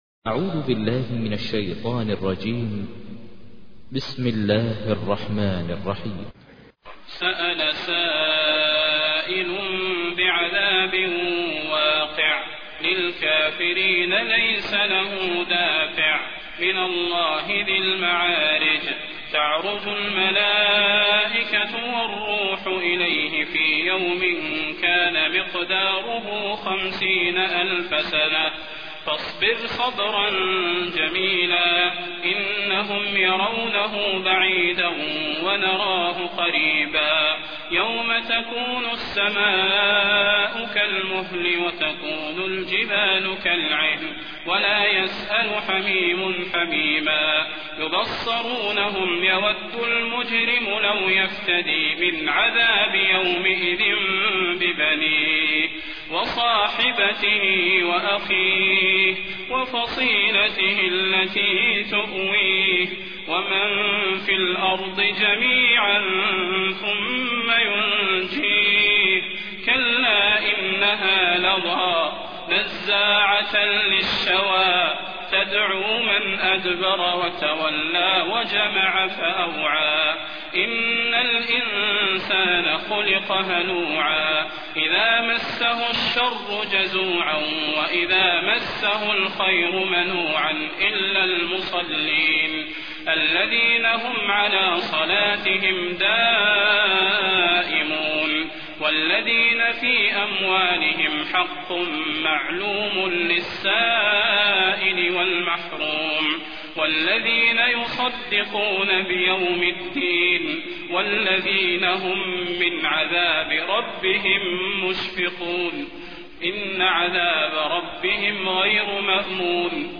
تحميل : 70. سورة المعارج / القارئ ماهر المعيقلي / القرآن الكريم / موقع يا حسين